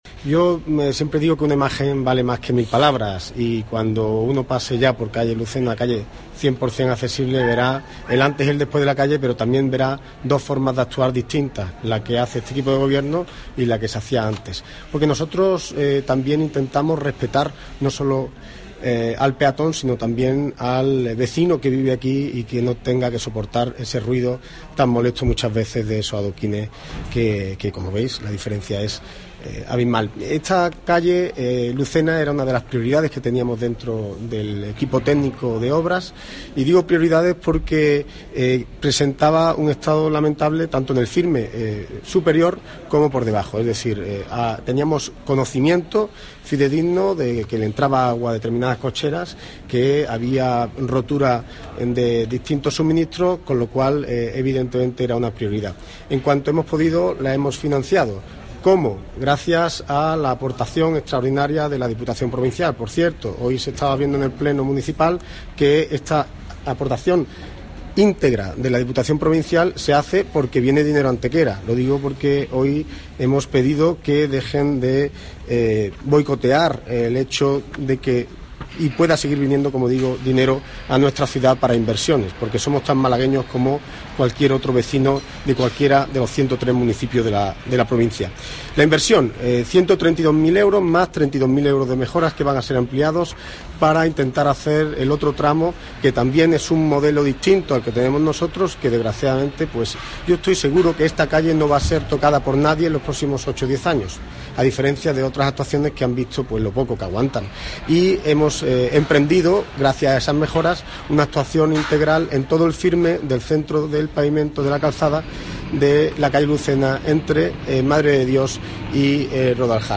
El alcalde de Antequera, Manolo Barón, y el teniente de alcalde delegado de Obras y Mantenimiento, José Ramón Carmona, han informado esta mañana de la reapertura al tráfico de vehículos del tramo de calle Lucena comprendido entre la Cruz Blanca y la intersección con las calles Mancilla y San José.
Cortes de voz